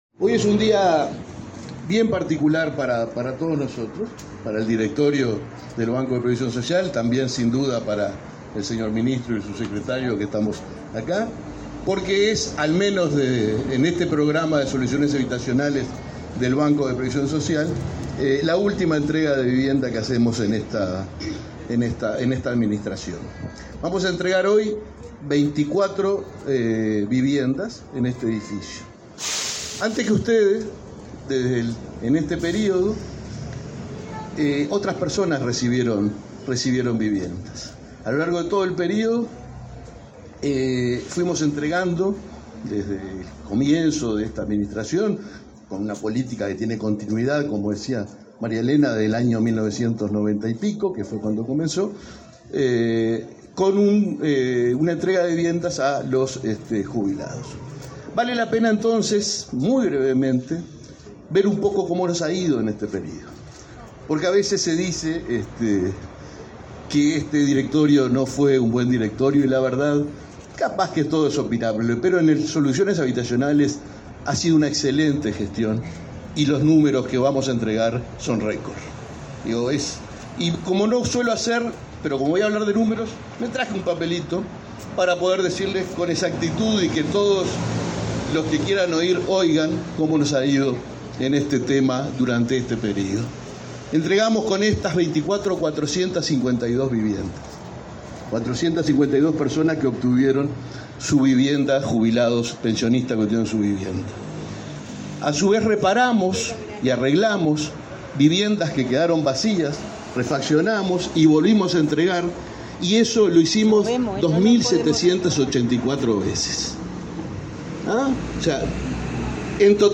Acto de entrega de viviendas para jubilados y pensionistas del BPS
Acto de entrega de viviendas para jubilados y pensionistas del BPS 14/02/2025 Compartir Facebook X Copiar enlace WhatsApp LinkedIn El Ministerio de Vivienda y Ordenamiento Territorial (MTOV), en coordinación con el Banco de Previsión Social (BPS) entregó, este 14 de febrero, 30 viviendas para jubilados y pensionistas. Participaron en el evento el presidente del BPS, Alfredo Cabrera, y el titular del MVOT, Raúl Lozano.